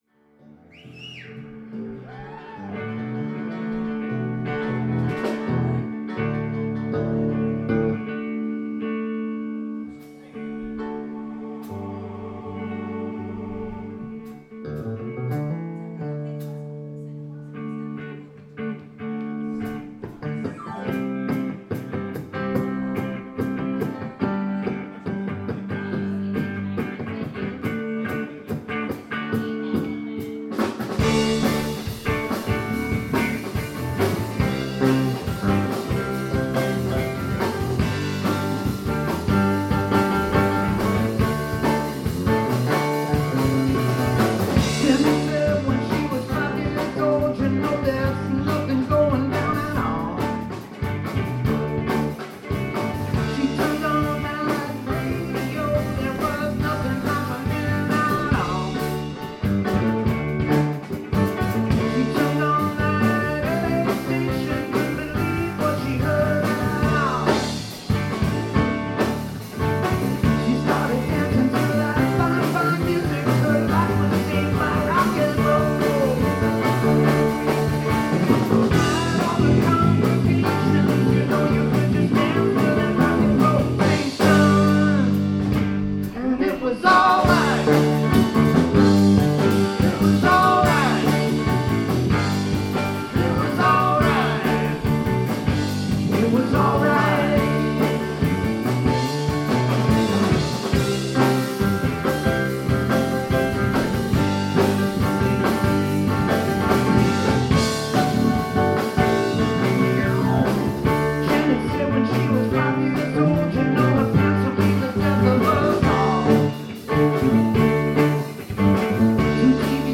a landmark of live music for over 45 years
and they deliver a tight, rockin’ set
which features a slew of special guests on stage
vocals